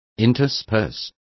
Complete with pronunciation of the translation of interspersed.